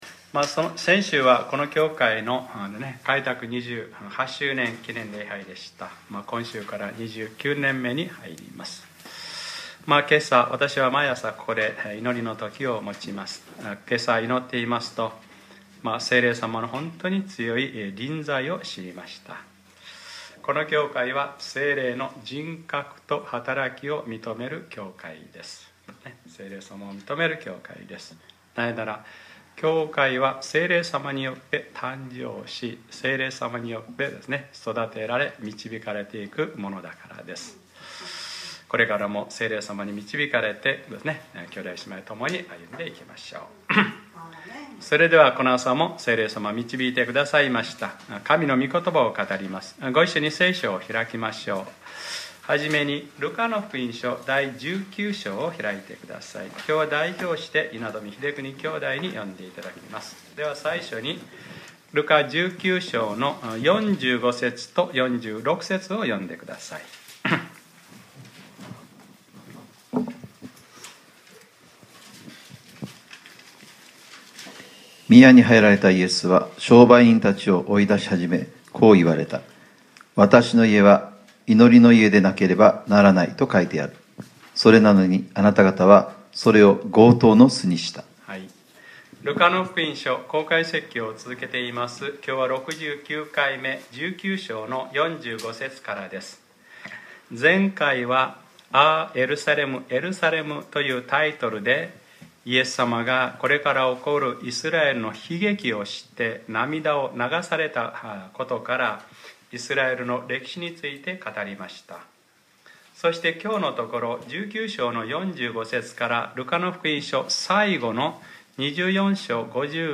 2015年10月11日（日）礼拝説教 『ルカｰ６９：何の権威によって』